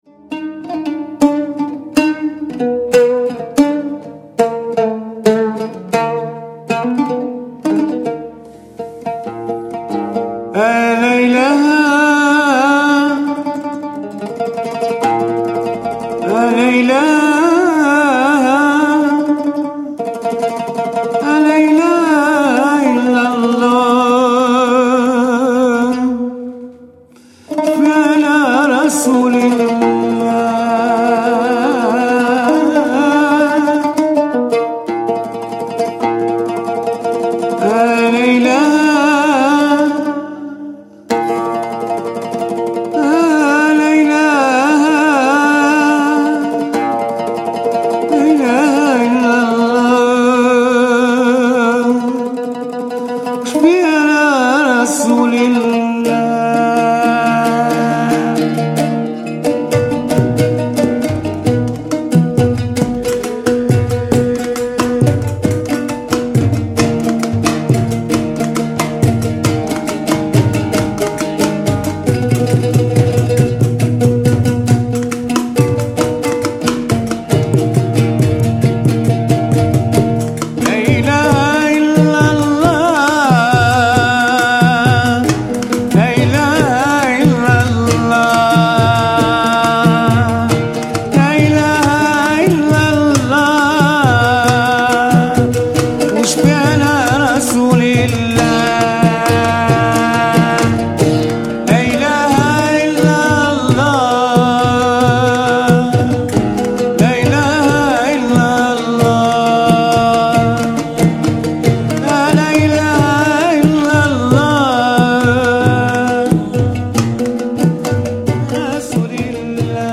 Sufimusik aus Nordafrika, Sudan, Algerien und der Sahelzone
Mit Geige, Oud, Gesang und verschiedenen Trommeln
Form der Sufimusik